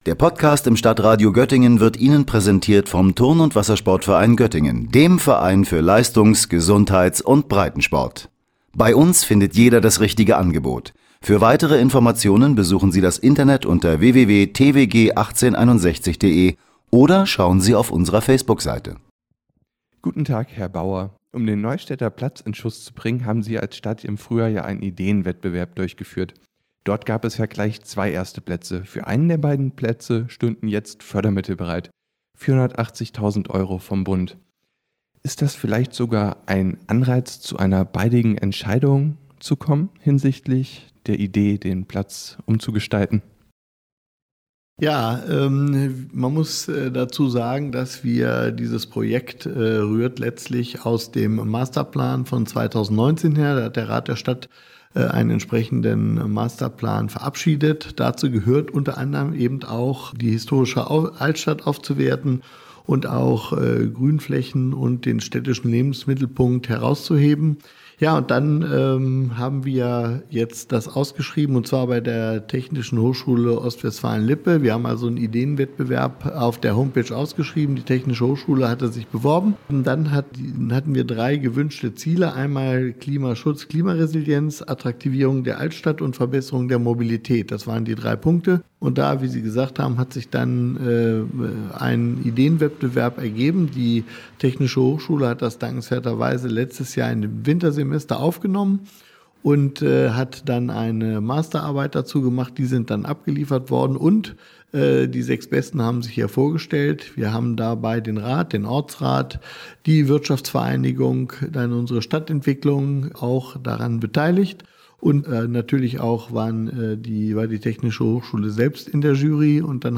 Fördermittel für Uslars Neustädter Platz laufen bis Juni 2026 – Bürgermeister Torsten Bauer im Interview